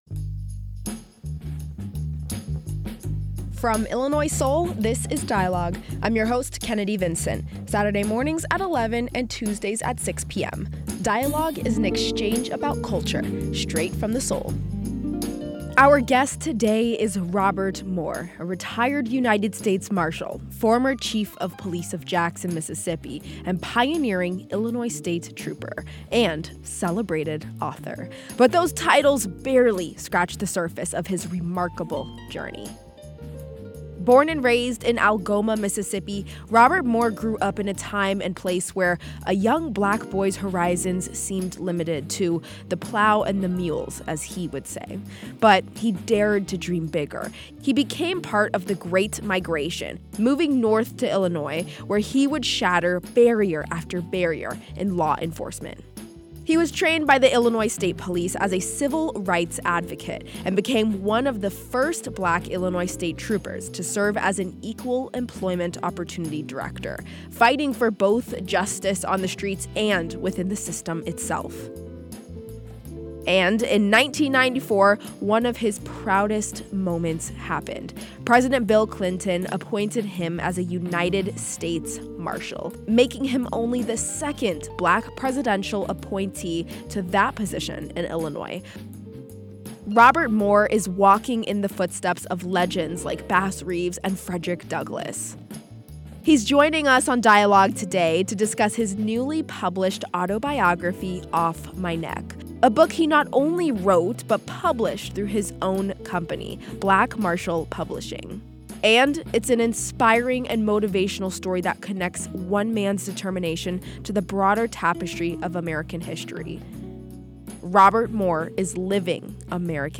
Robert Moore Central Illinois’ first Black U.S. Marshal shares details on his journey through the Illinois State Police and how he fought for civil rights within the realm of law enforcement.
Today he discusses his newly published autobiography, “Off My Neck”—a book published through his own company, Black Marshal Publishing. __ GUESTS: Robert Moore Retired United States Marshal, former Chief of Police of Jackson, Mississippi, pioneering Illinois State Trooper, and celebrated author.